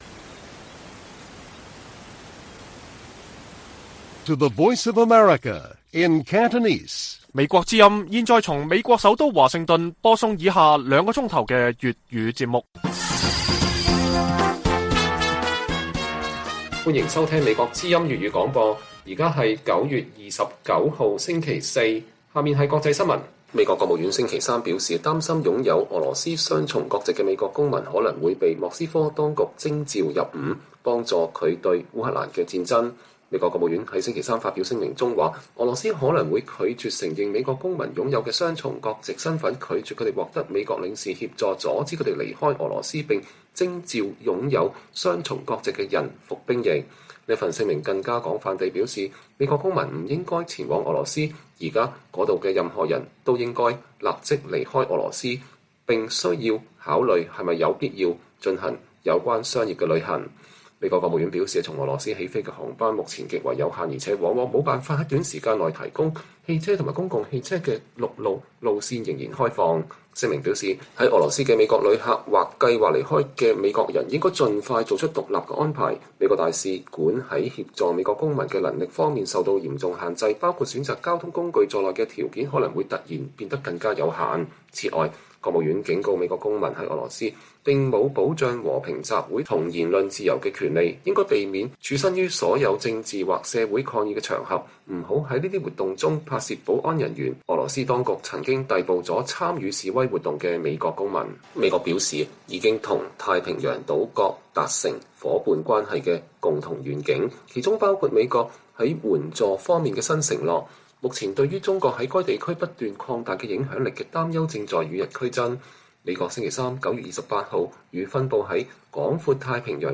粵語新聞 晚上9-10點: 美國國務院呼籲美國公民迅速離開俄羅斯